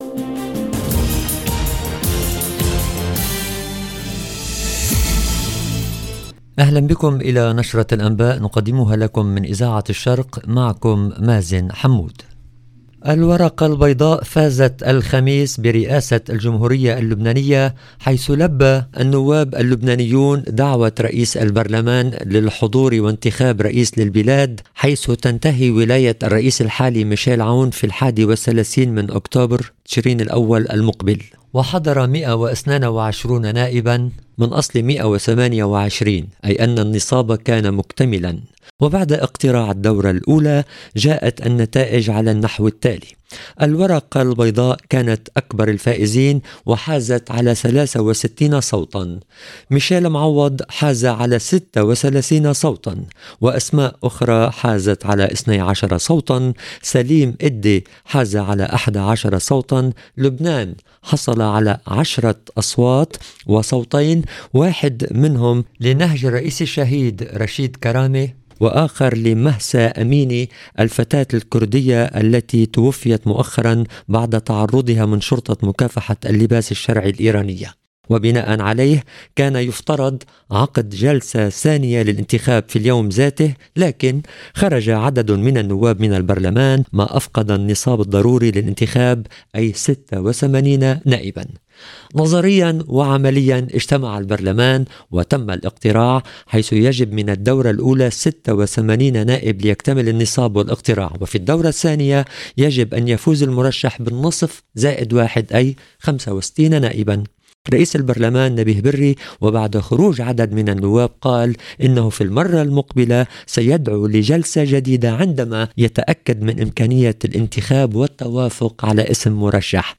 LE JOURNAL DU SOIR EN LANGUE ARABE DU 29/09/22